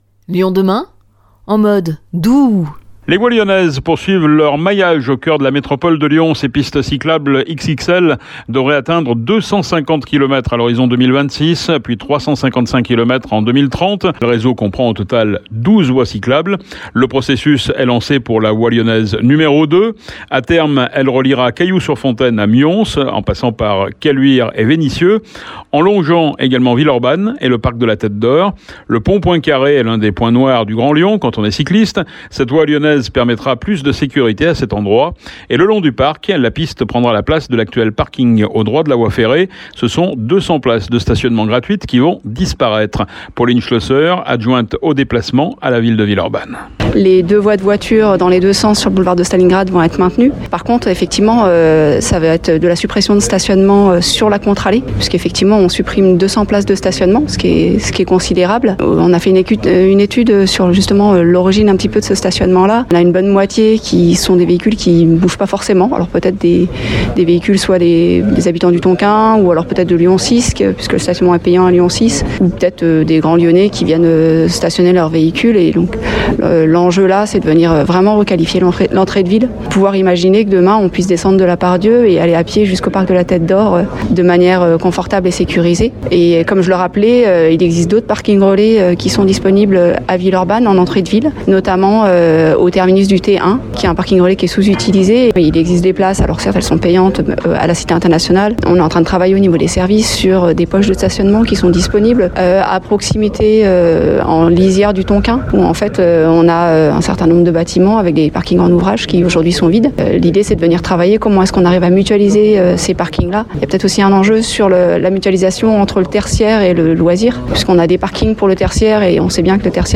Nous avons rencontré Fabien Bagnon, vice-président délégué aux mobilités actives et à la voirie à la Métropole, et Pauline Schlosser, adjointe aux déplacements, à la ville de Villeurbanne.